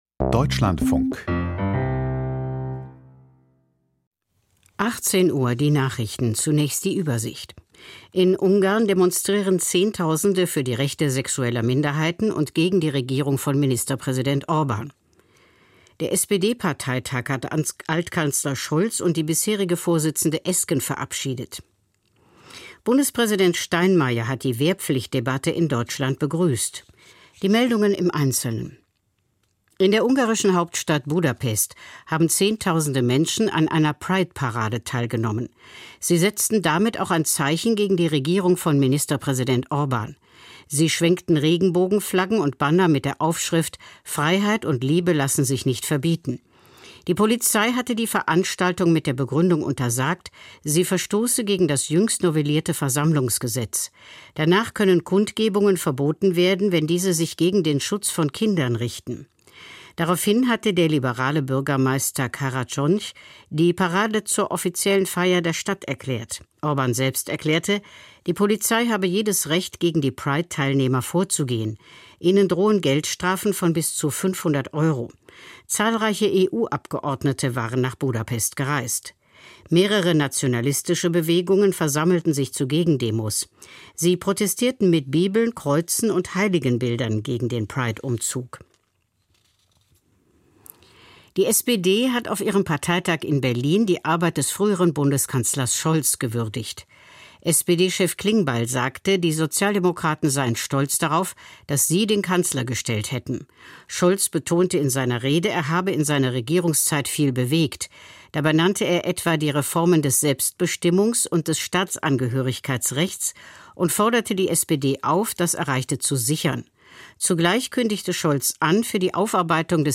Die Nachrichten vom 28.06.2025, 18:00 Uhr